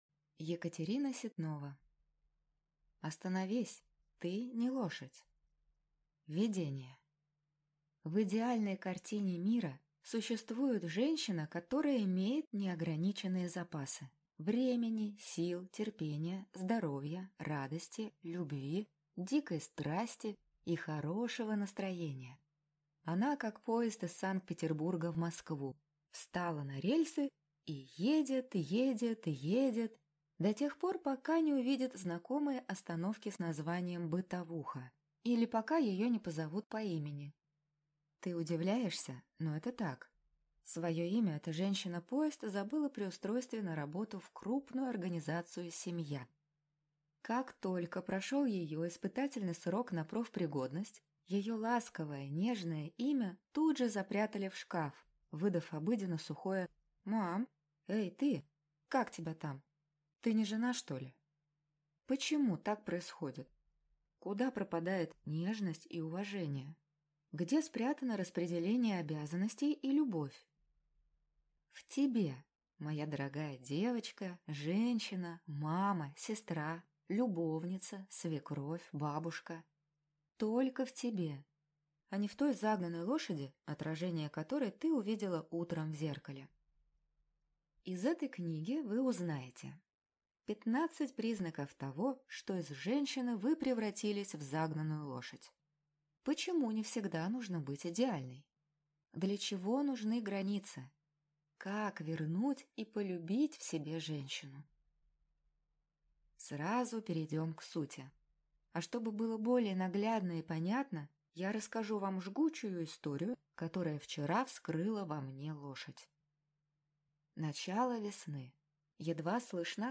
Аудиокнига Остановись! Ты – не лошадь | Библиотека аудиокниг